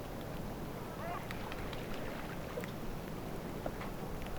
tuollainen lokin ääni, 1
Koska nämä lokit eivät varsinaisesti ole
tuollainen_lokin_aani_1.mp3